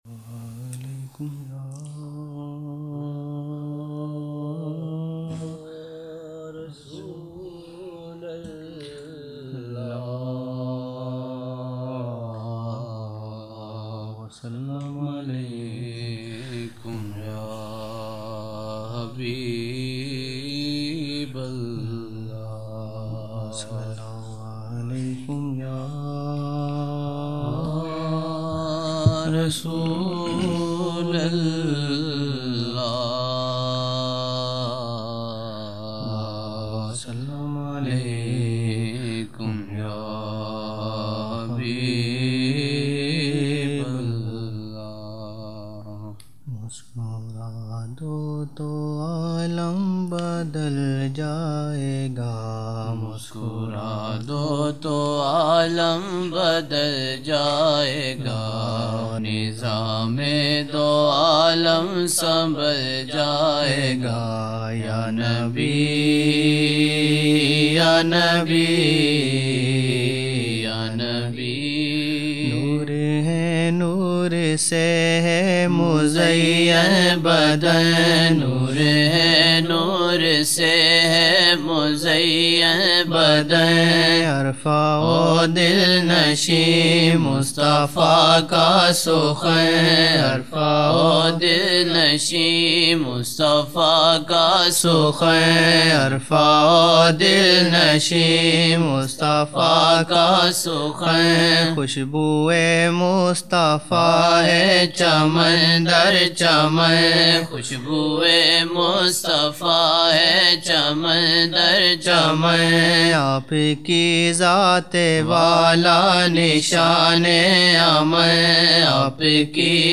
Old Naat Shareef